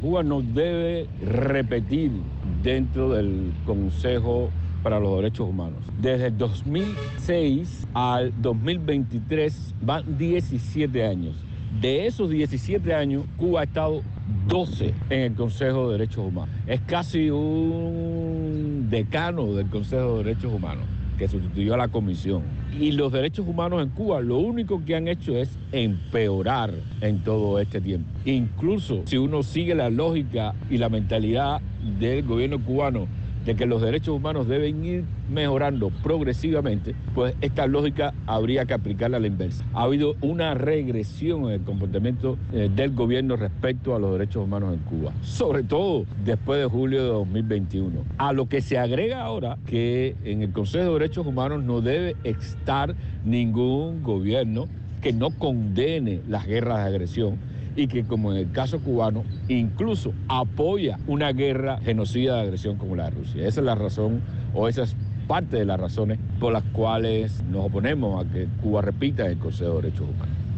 habla para Martí Noticias